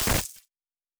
Glitch 2_01.wav